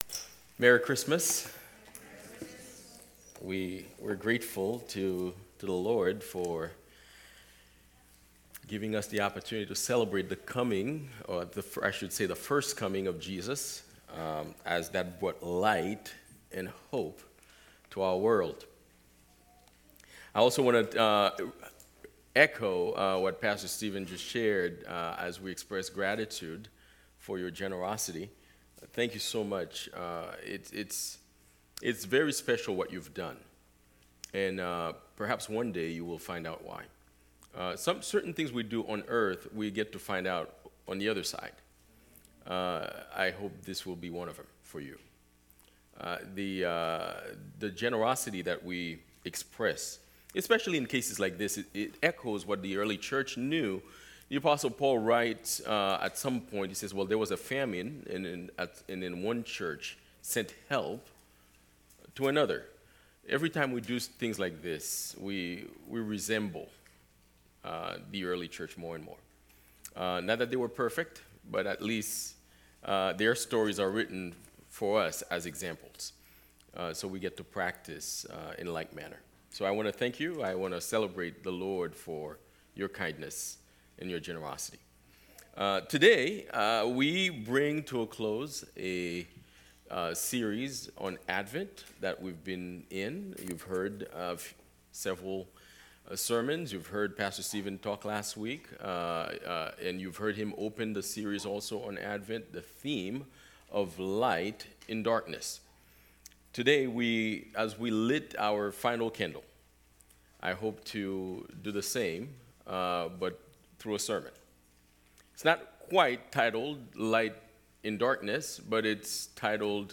Sermons by Fellowship Church Dedham